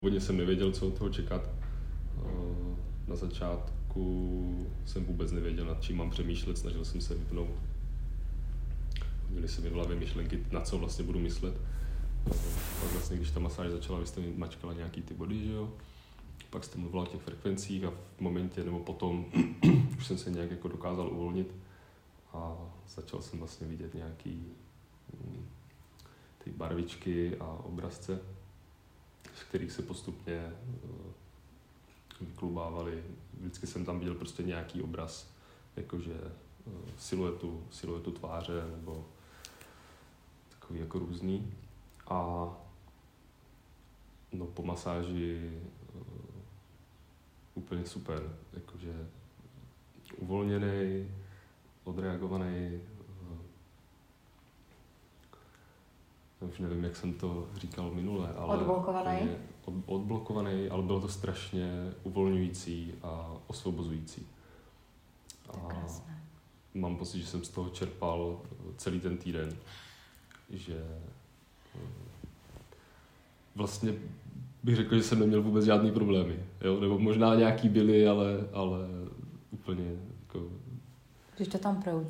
Recenze klienta: